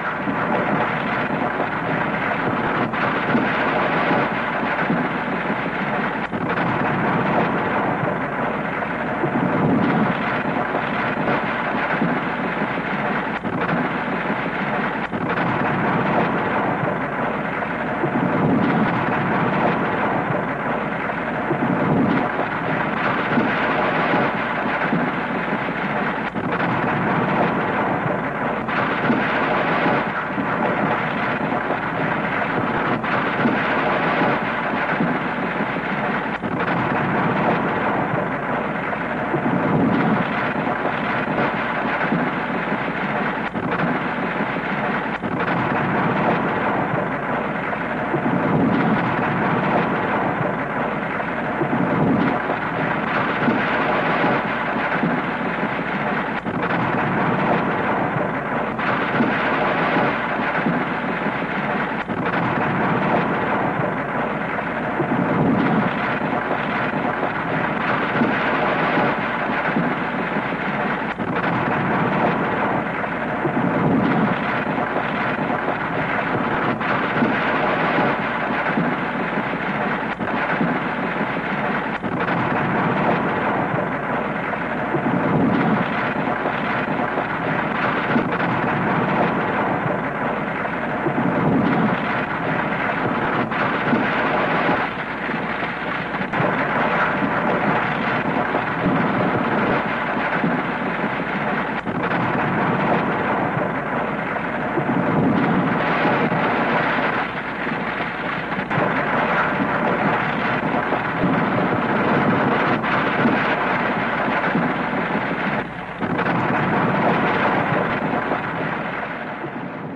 地震効果音約120秒（WAV形式 約21MB）
地震効果音はフリー音源を上記の秒数に編集したものです。